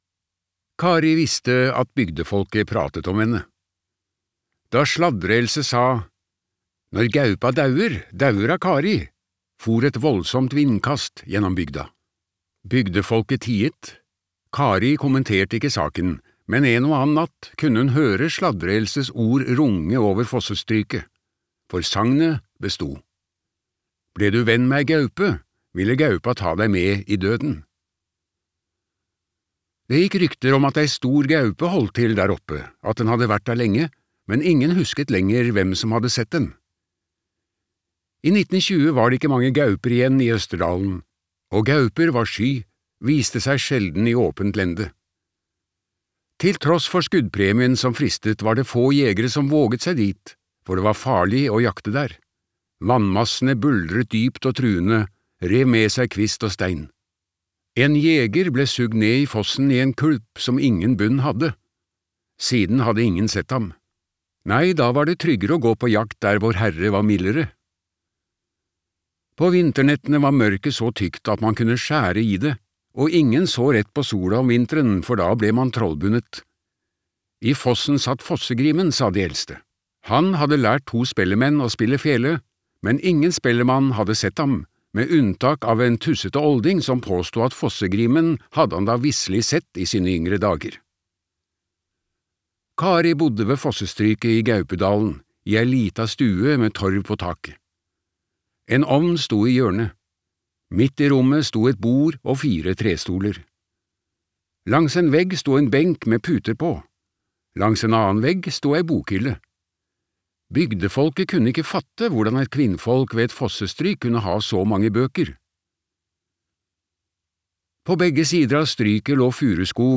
Det sjette barnet var en gutt - roman (lydbok) av Therese Lund Stathatos